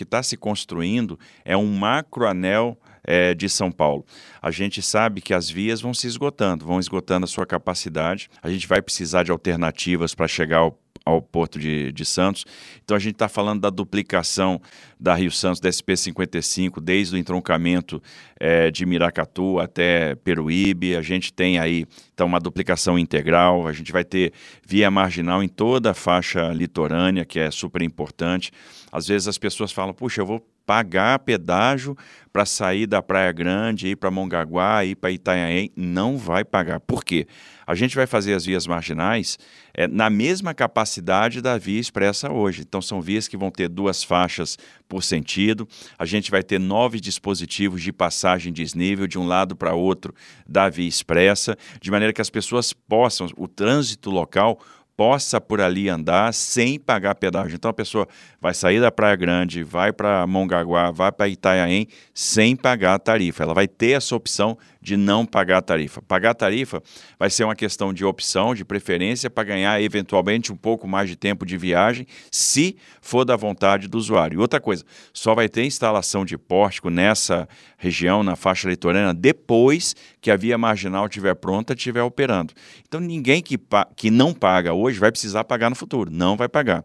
O governador Tarcísio de Freitas falou sobre como será feita a cobrança de pedágios no litoral paulista, e estuda alternativas para beneficiar outras comunidades, como Caruara, em Santos. O contrato do Lote Litoral Paulista prevê investimentos de R$ 4,3 bilhões, incluindo duplicação de rodovias, marginais e melhorias estruturais na Baixada Santista, Alto Tietê e Vale do Ribeira.